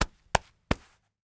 household
Coins in Pants Pocket Hands Patting